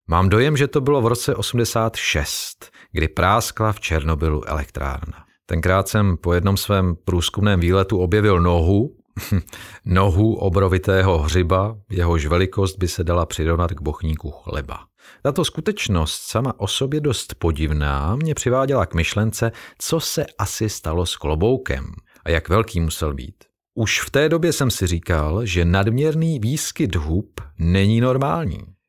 Umím: Voiceover
Vzhledem k sedmileté praxi v divadelní činohře jsem schopen s hlasem pracovat a přizpůsobit jej Vašim přáním a požadavkům.
Schwarz - autorská povídka - ukázka.mp3